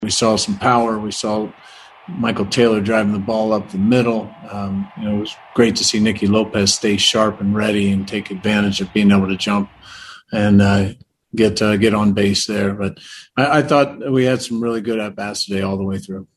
Royals manager Mike Matheny said big hits were key to the win.